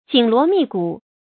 緊鑼密鼓 注音： ㄐㄧㄣˇ ㄌㄨㄛˊ ㄇㄧˋ ㄍㄨˇ 讀音讀法： 意思解釋： 鑼鼓點敲得很密。比喻公開活動前的緊張的輿論準備。